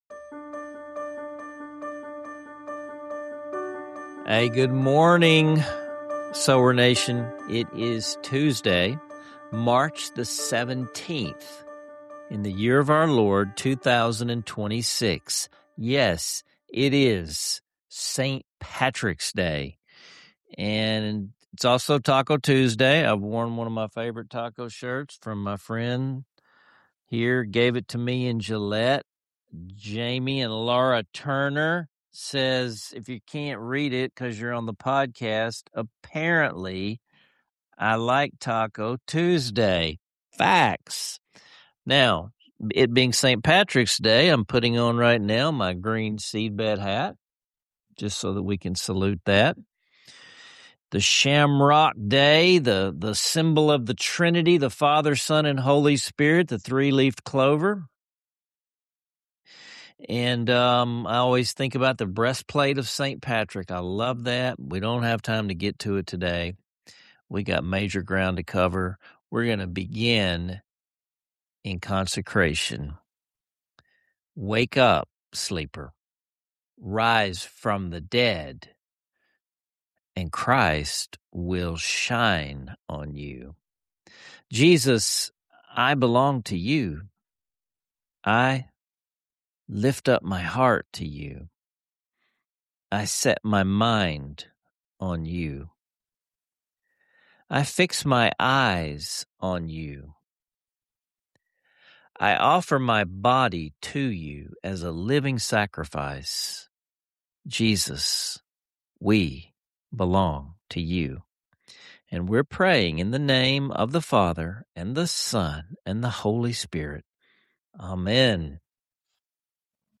As St. Patrick’s Day meets Taco Tuesday, you’ll enjoy a burst of enthusiasm, humor, and spiritual insight, all set against the backdrop of treasured hymns and personal stories.